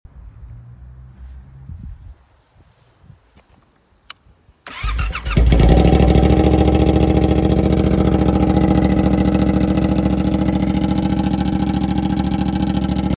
Yamaha MT07 no exhaust sound. sound effects free download
BRUTAL!